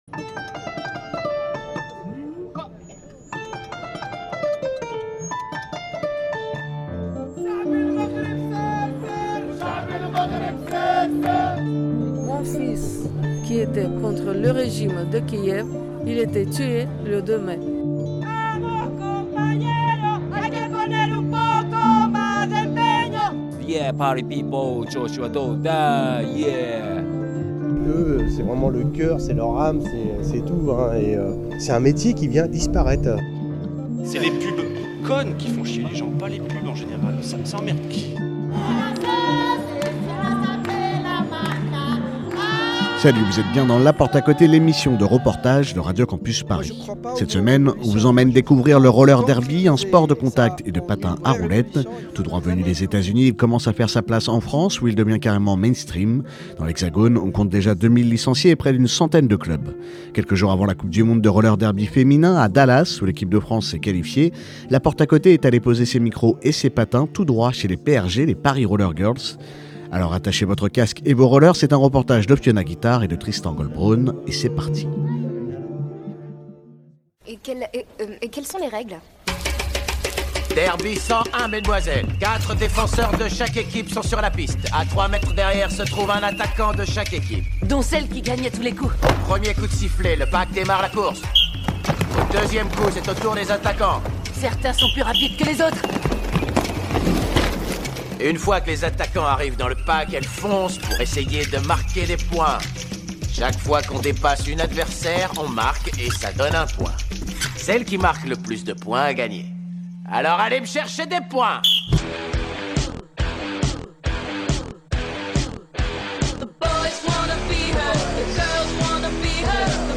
A quelques jours de la coupe du monde de Roller Derby féminin, à Dallas, La Porte à Côté est allée poser ses micros sur la piste des PRG : les Paris Rollers Girls .